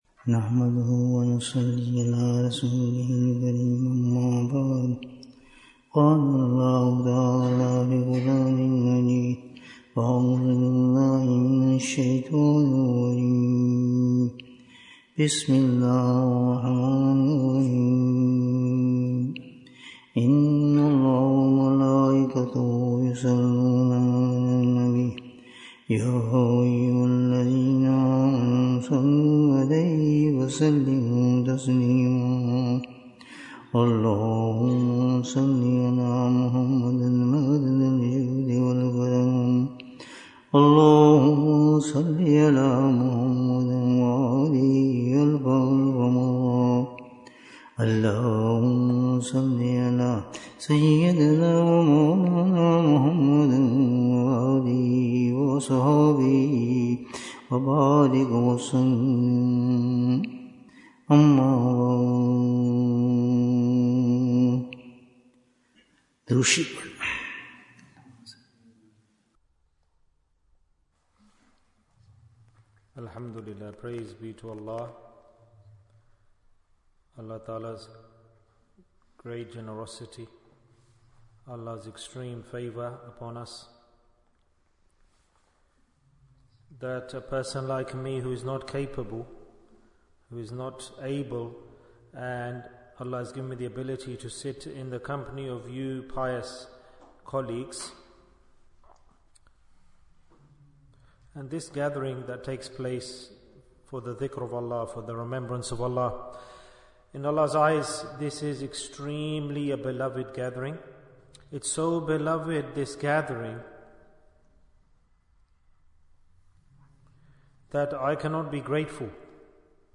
What is Iman? Bayan, 102 minutes12th December, 2024